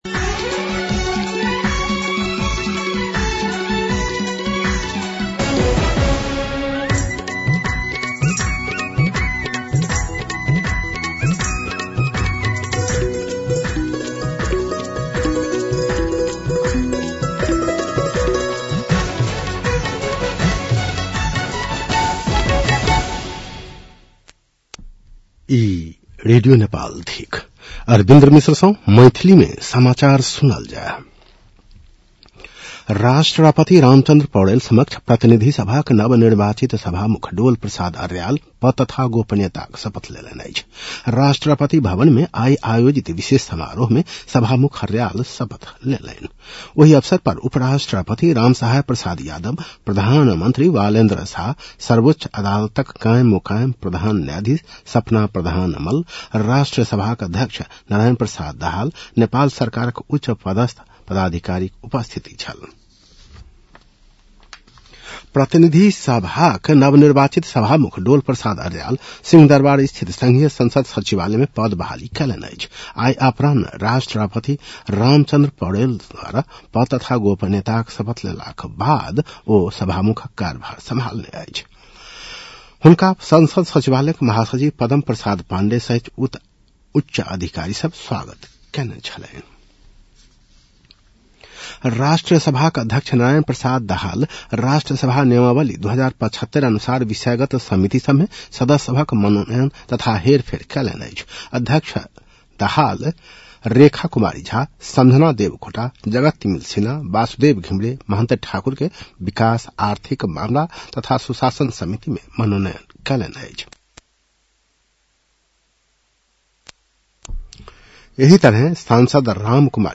An online outlet of Nepal's national radio broadcaster
मैथिली भाषामा समाचार : २२ चैत , २०८२